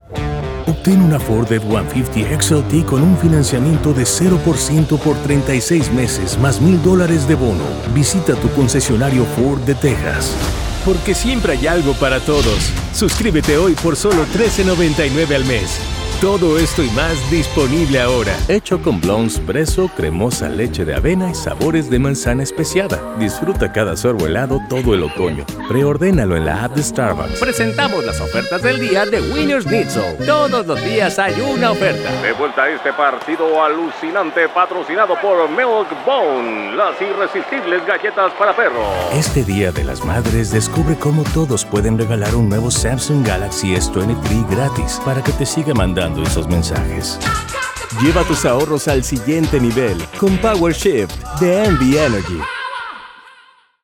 Locutor Voice Actor SAG-AFTRA Author